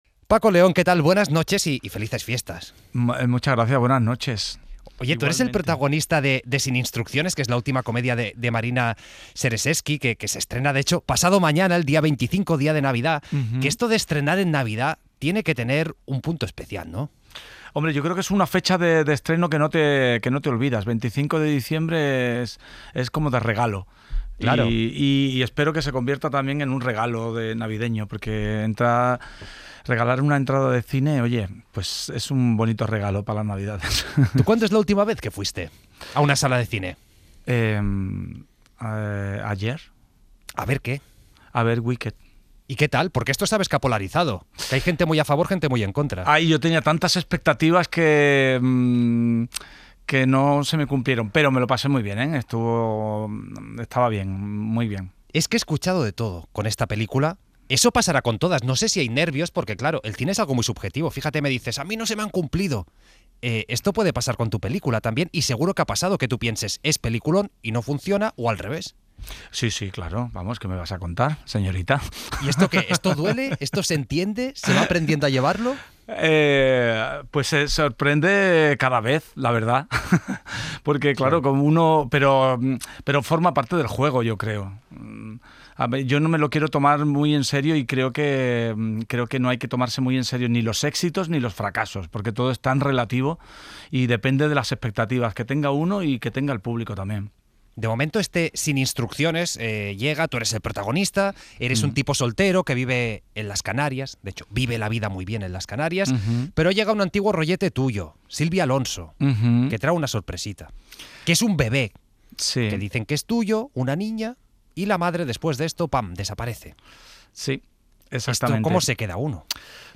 La Entrevista | Paco León